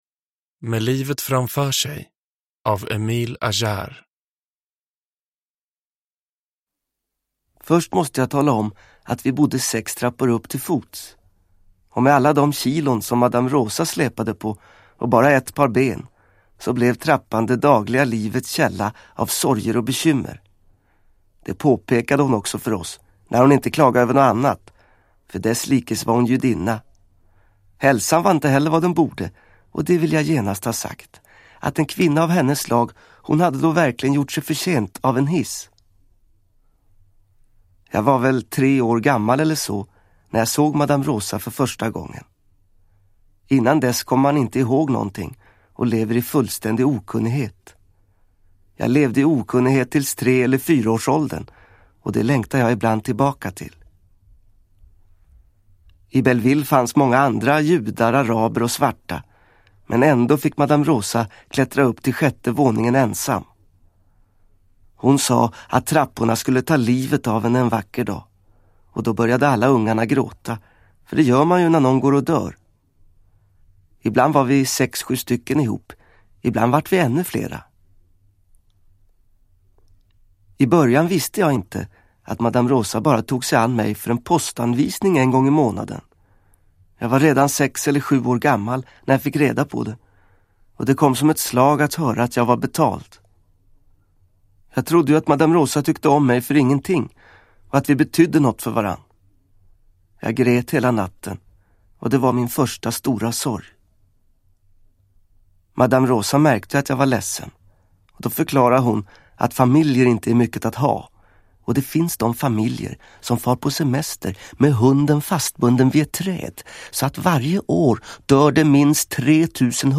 Med livet framför sej – Ljudbok – Laddas ner
Inspelningen är från Sveriges Radio AB, 1985.
Uppläsare: Johan Ulveson